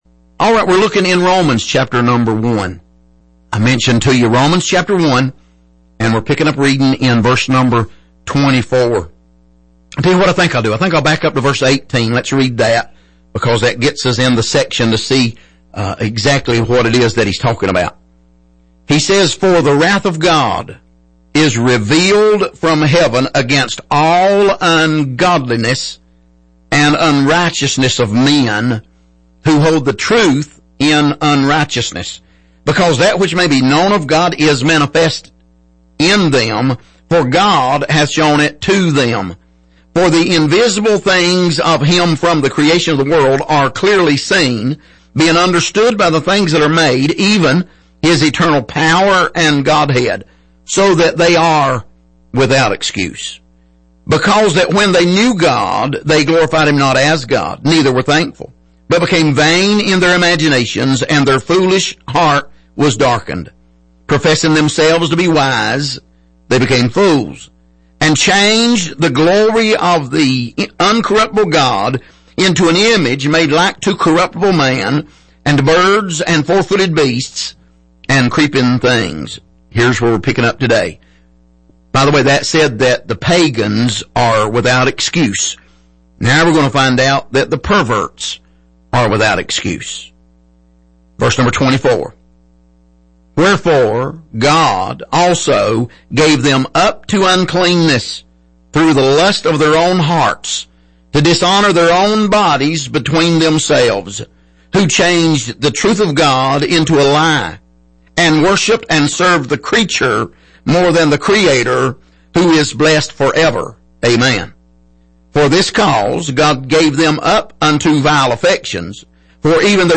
Passage: Romans 1:18-32 Service: Sunday Morning